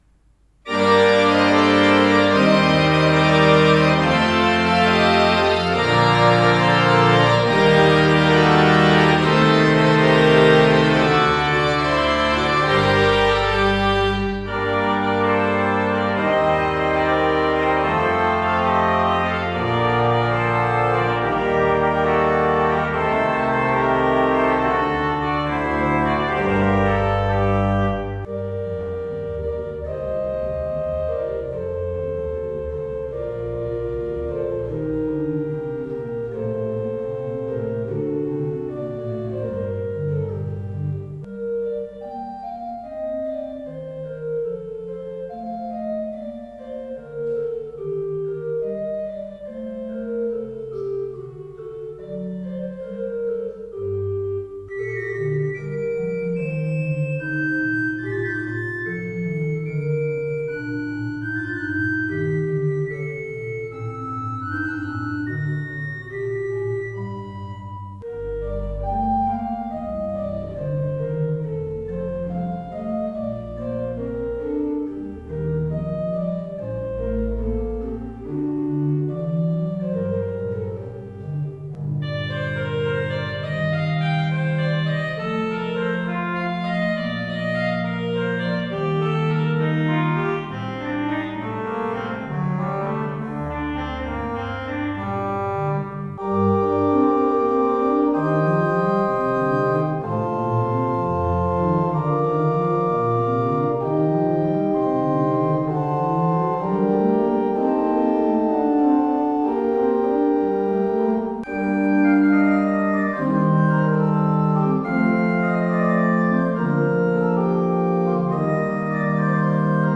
Orgel
Die Mühleisen Orgel
Klanglich orientiert sich das Instrument am französischen Orgelstil.